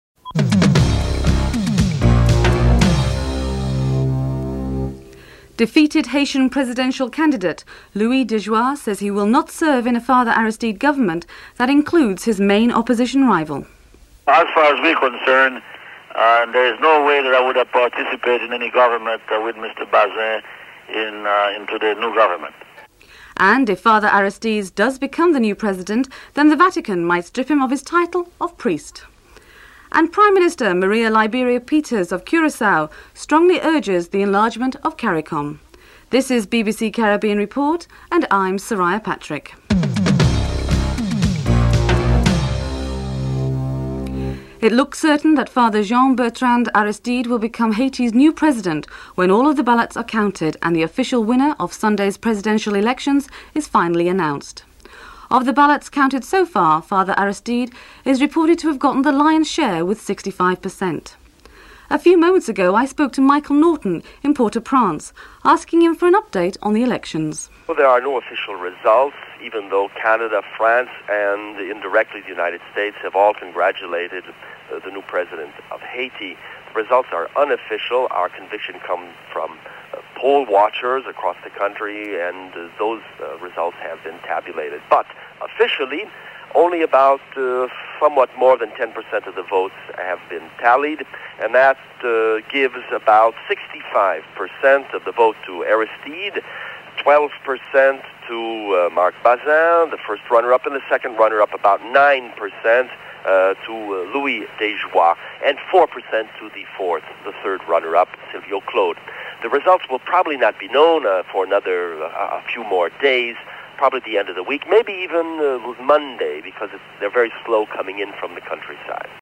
1. Headlines (00:00-00:43)
Maria Liberia-Peters, Prime Minister of Curacao, strongly urges the enlargement of Caricom with Haiti coming onboard (09:35-14:54)